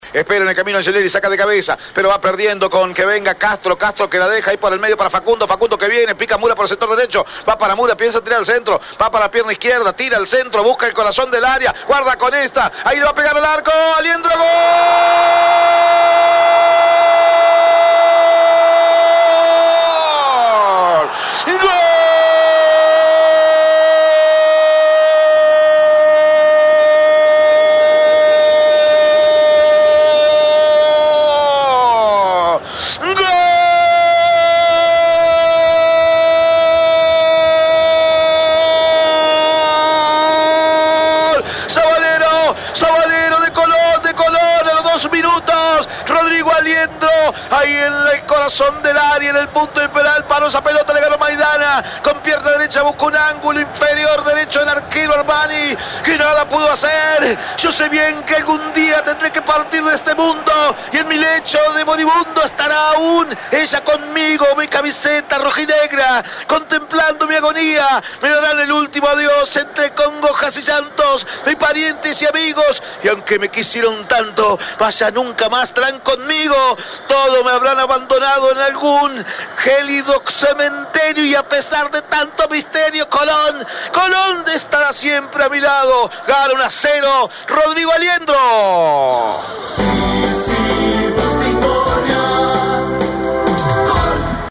01-GOL-COLON.mp3